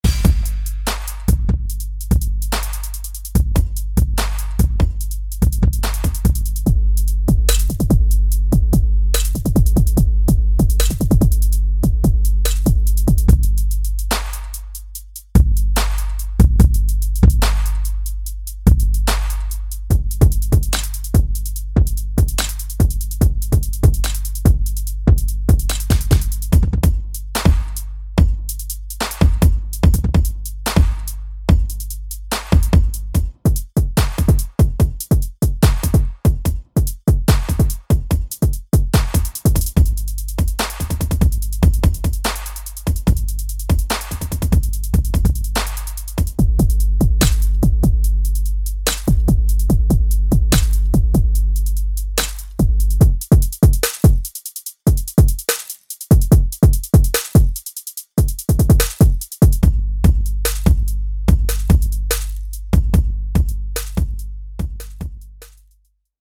这款音色包融合了合成鼓、碎玻璃和金属撞击声，能带来震撼的音效。
• 10 个循环乐段